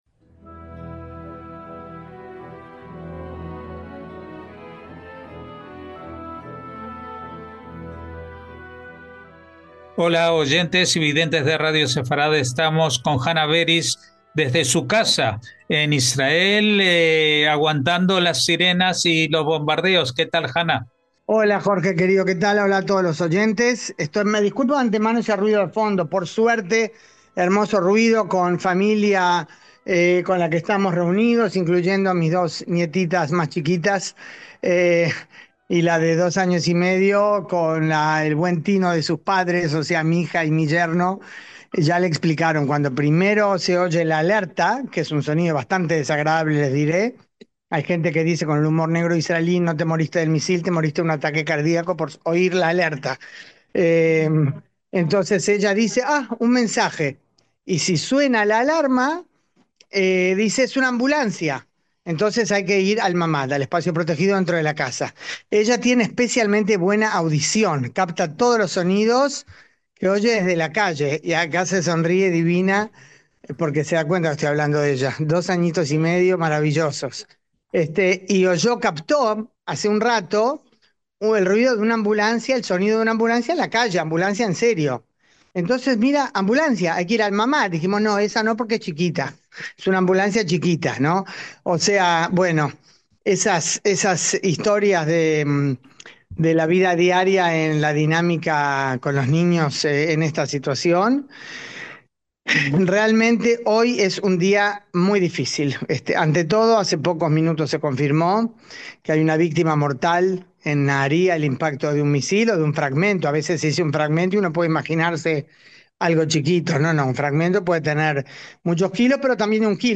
sonó la alarma que obligó a todos los ocupantes de su casa a resguardarse en el refugio
describiéndonos en directo lo que pasaba y las sensaciones de la población civil israelí